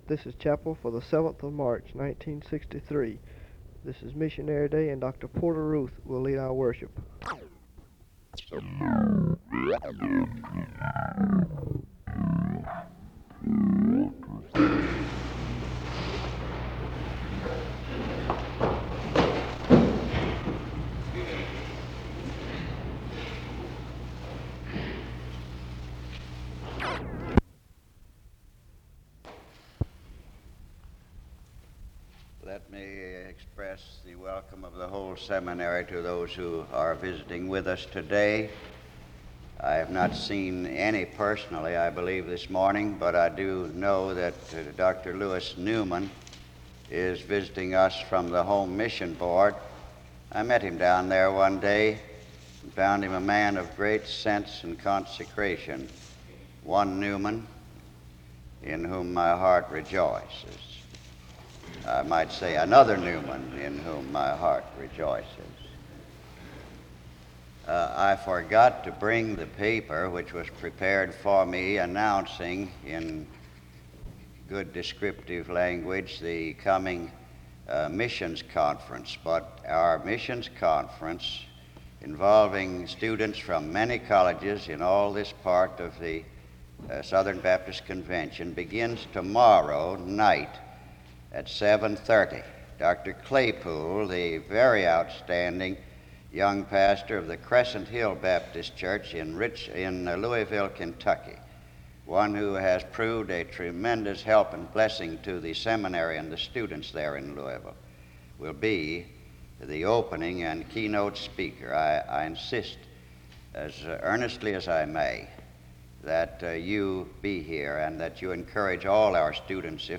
Wake Forest (N.C.)
SEBTS Chapel and Special Event Recordings